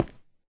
stone2.ogg